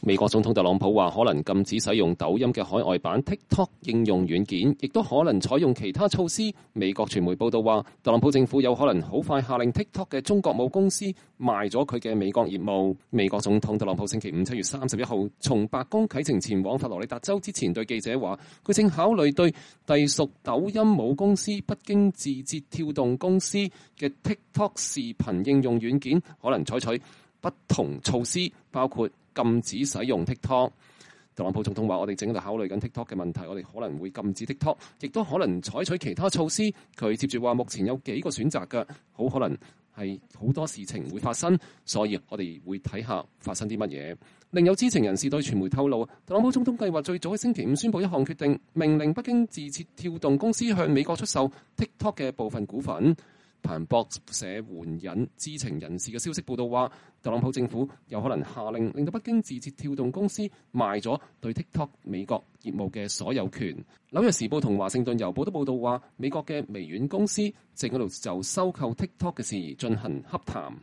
特朗普總統離開白宮前往佛羅里達州之前對媒體發表講話。（2020年7月31日）